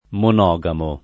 [ mon 0g amo ]